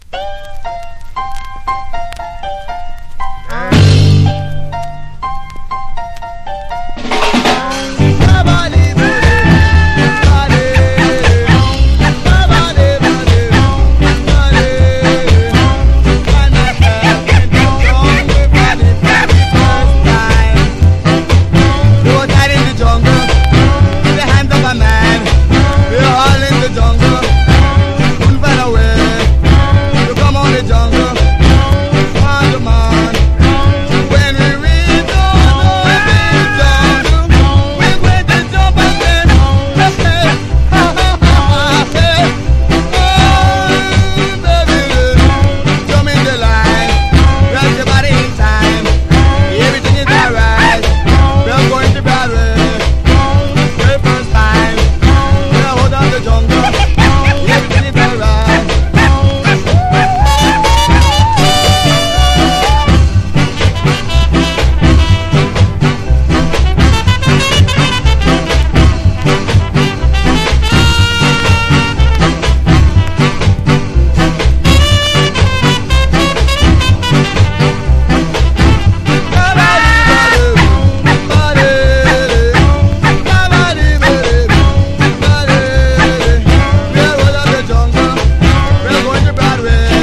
SKA / ROCK STEADY
(ジャマイカ盤特有のチリノイズ入ります　盤面に油汚れ有り)